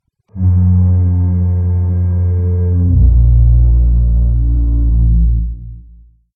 Fog Horn
air boat dock fog horn tug sound effect free sound royalty free Memes